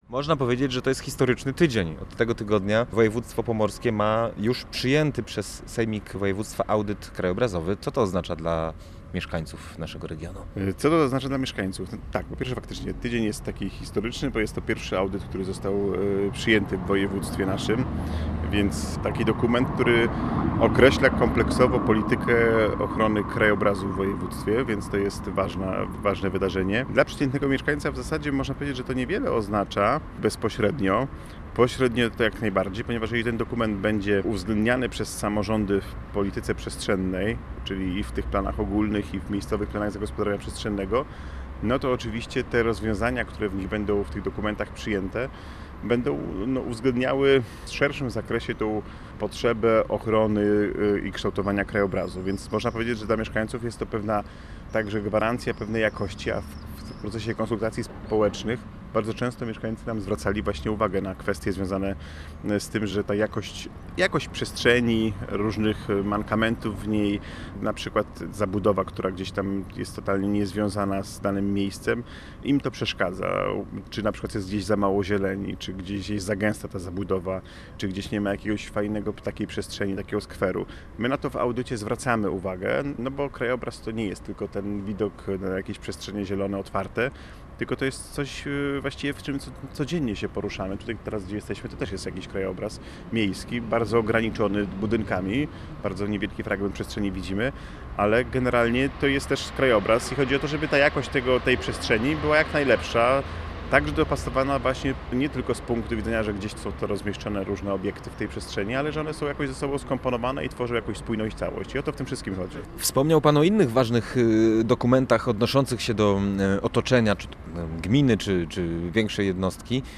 audyt Gość Radia Gdańsk TL uchwala krajobrazowa